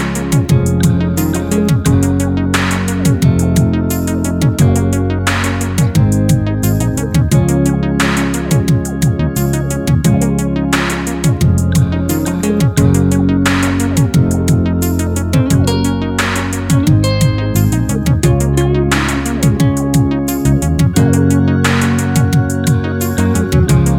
End Cut Down With No Backing Vocals Reggae 3:57 Buy £1.50